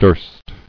[durst]